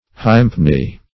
hympne - definition of hympne - synonyms, pronunciation, spelling from Free Dictionary Search Result for " hympne" : The Collaborative International Dictionary of English v.0.48: Hympne \Hymp"ne\, n. A hymn.